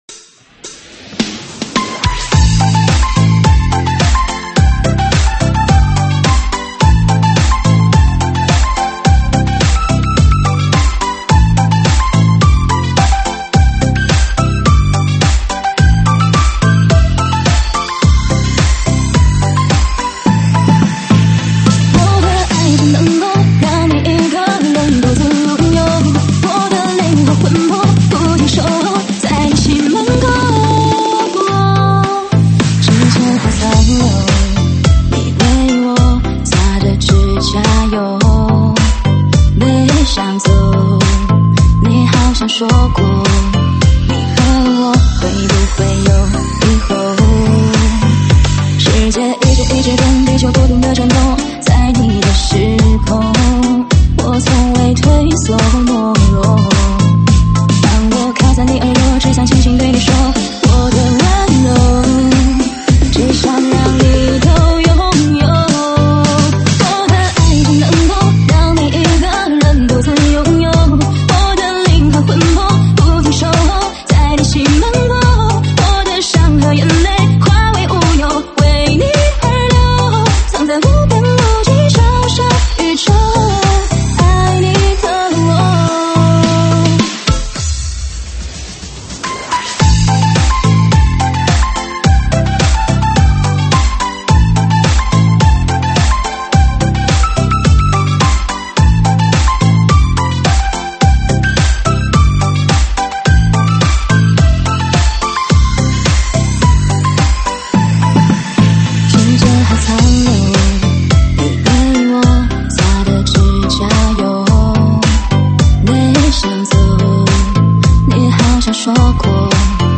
中文舞曲